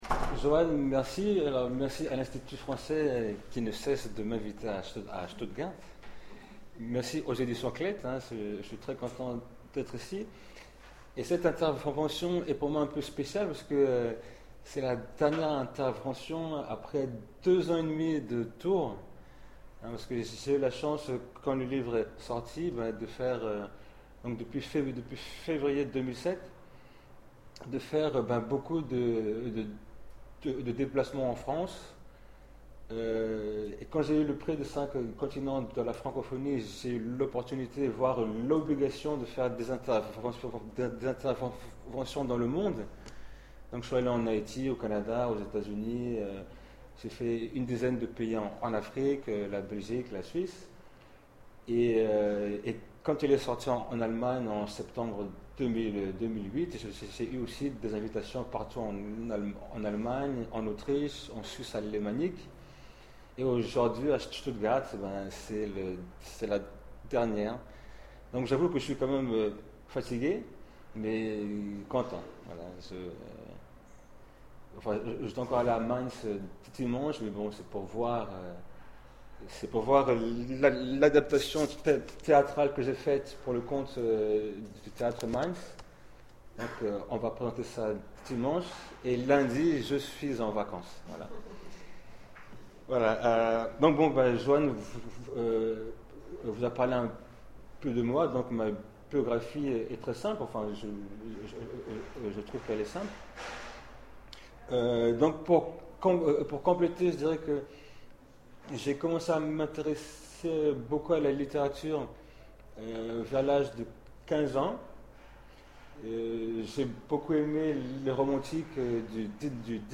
Lecture_TPS_Courte_Blog.mp3